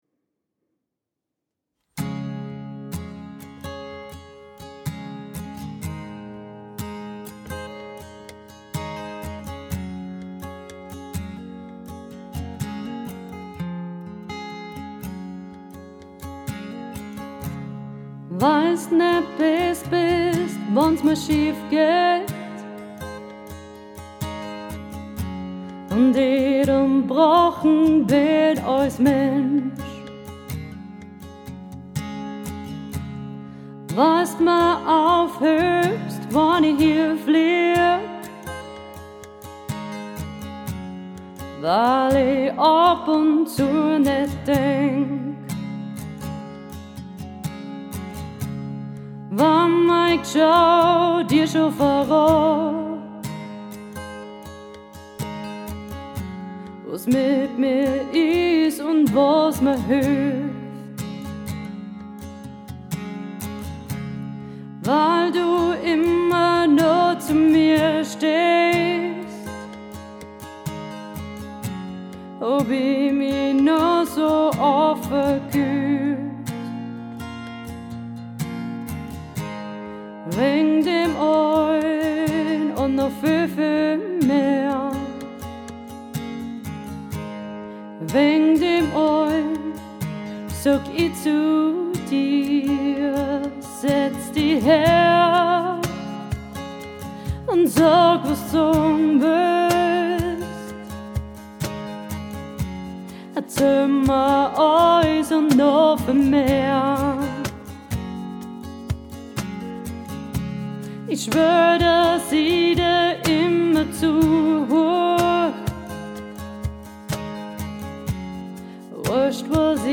romantic Cover Songs